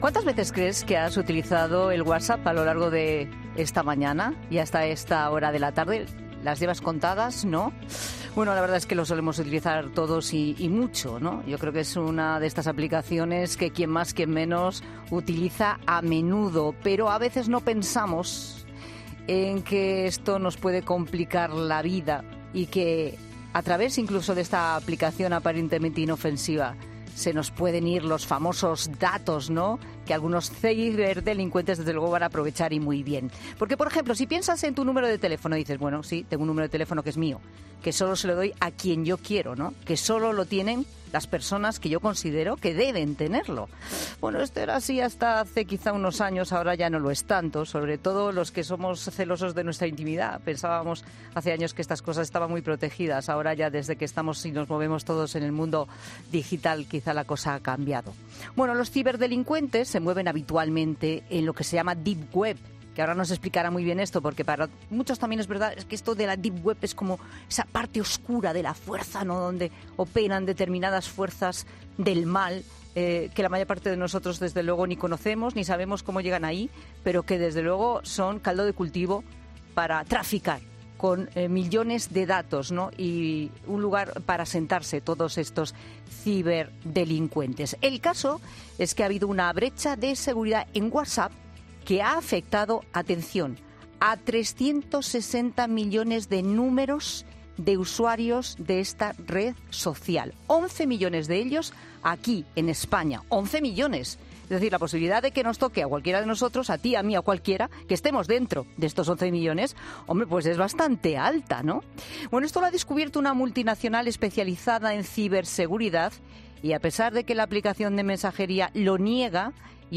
Lorenzo Silva, escritor y colaborador de 'La Tarde' de COPE nos ha ayudado a abordar el problema.